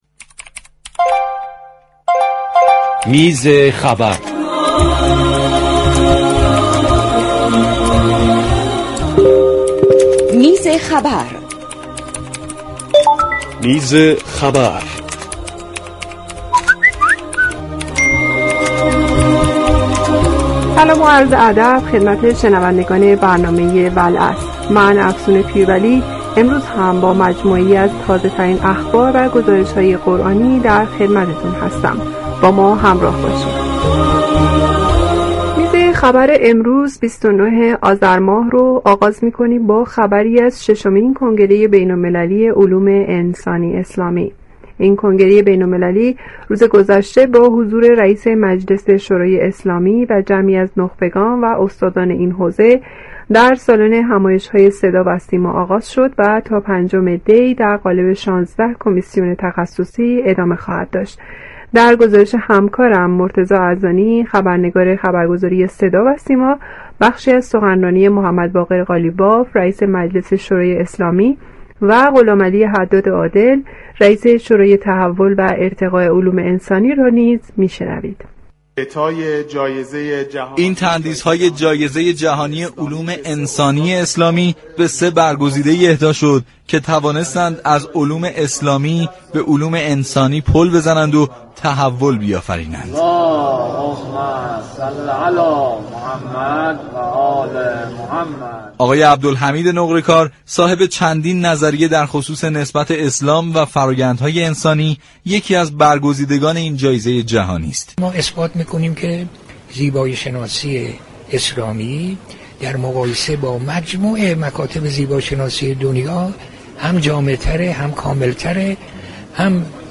در گفتگو با محمدباقر قالیباف رئیس مجلس شورای اسلامی و غلامعلی حداد عادل رئیس شورای تحول و ارتقای علوم انسانی.